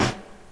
Snare (Ghost Town).wav